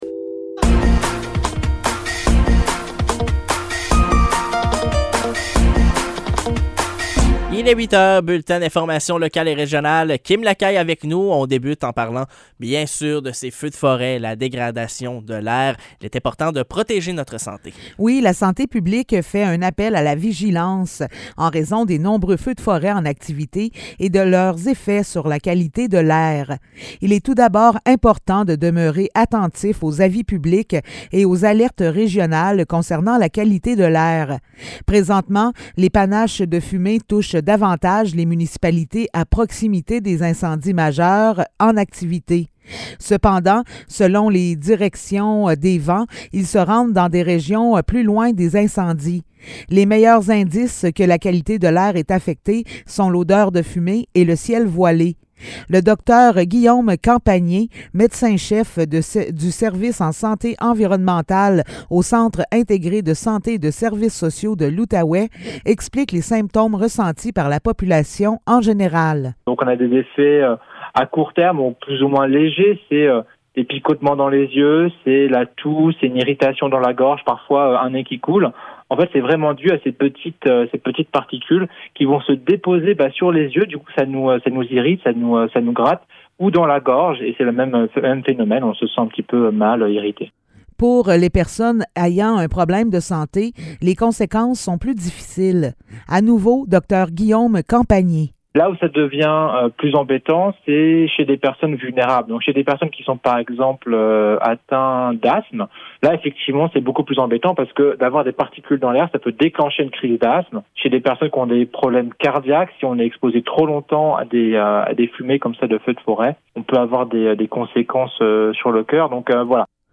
Nouvelles locales - 7 juin 2023 - 8 h